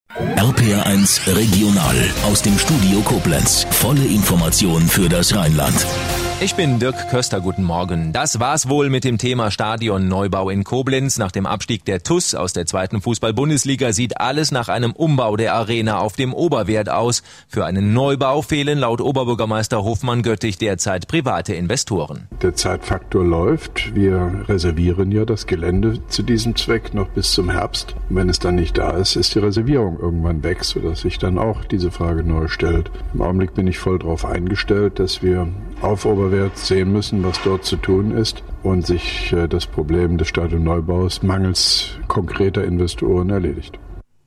RPR1 Regional, Studio Koblenz am 06.05.2010 (Dauer 00:44 Minuten)
Rundfunkinterview mit dem Koblenzer OB Joachim Hofmann-Göttig zum Thema TuS-Stadion